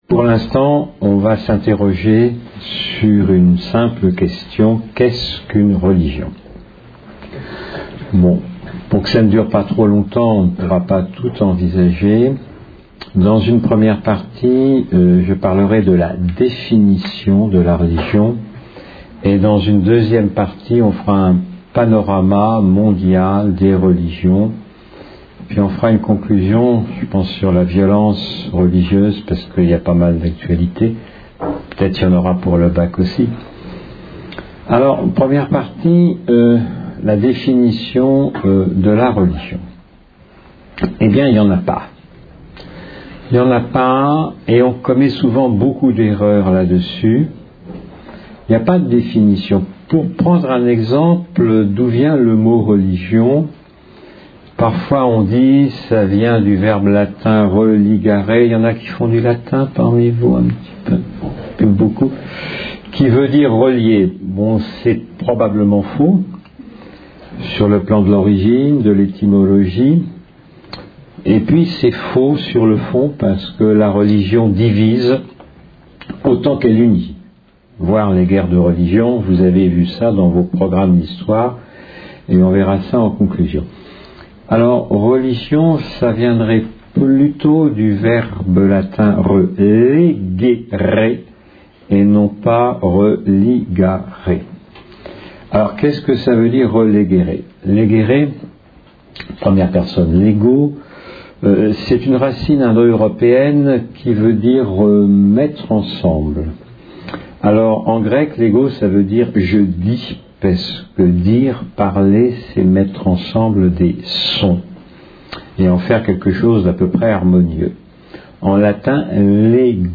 Une conférence de l'UTLS au Lycée Qu'est ce qu'une religion par Odon Vallet Lycée des Flandres (59 Hazebrouck)